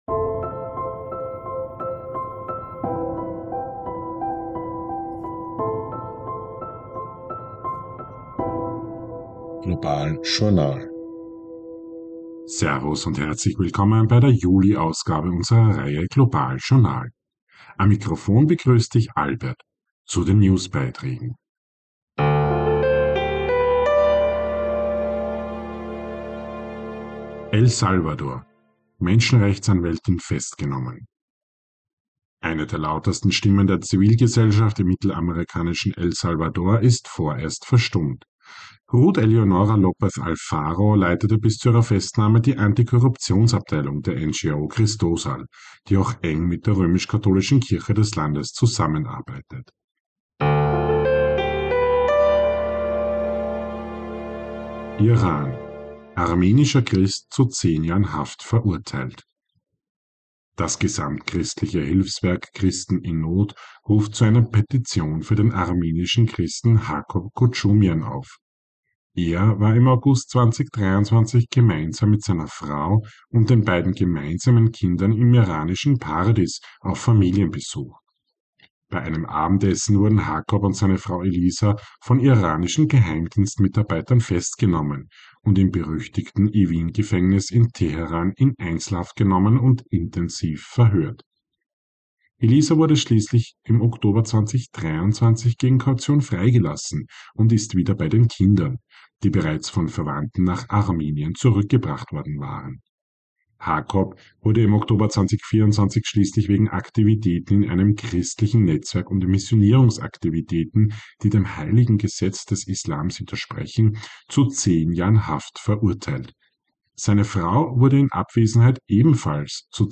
News Update Juli 2025